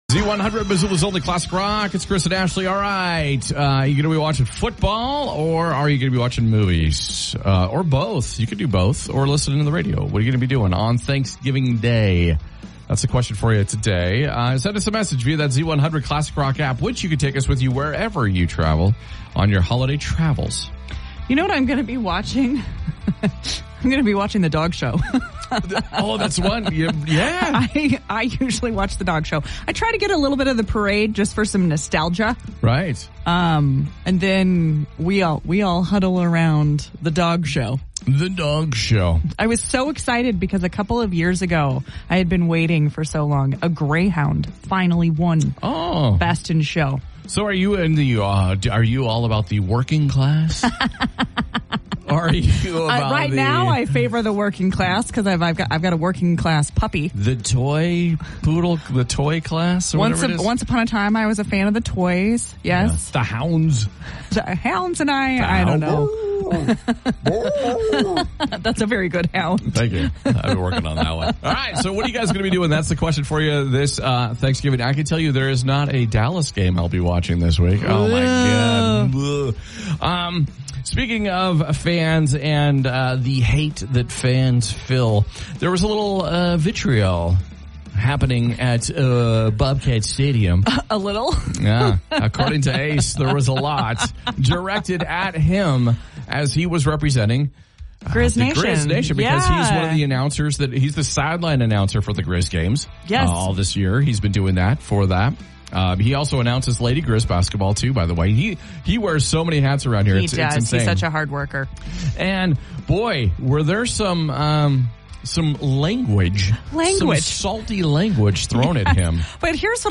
is continuing the tradition of being a local, entertaining morning radio show.